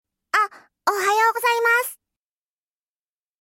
📌 Surprised & Polite → Adding “あっ！(A!)” gives a sense of surprise or realization, as if you suddenly noticed someone.